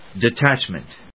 音節de・tách・ment 発音記号・読み方
/‐mənt(米国英語), di:ˈtætʃmʌnt(英国英語)/